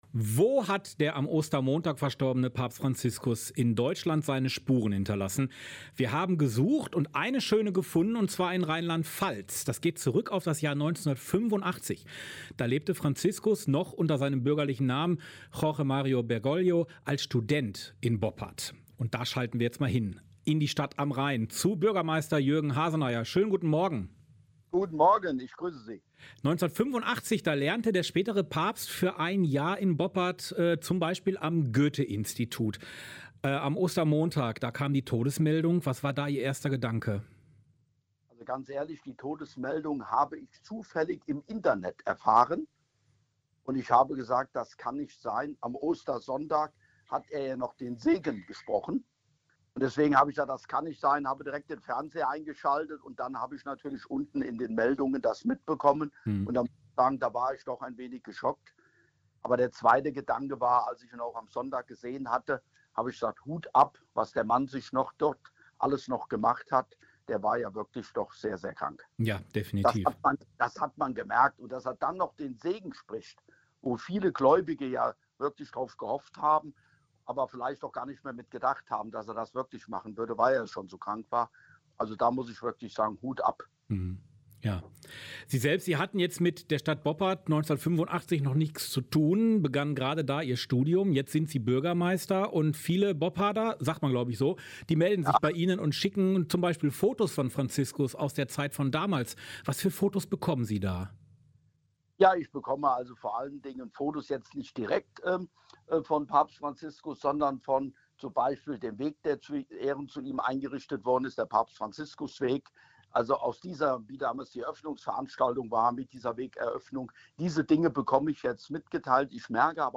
Ein Interview mit Jörg Haseneier (Bürgermeister von Boppard)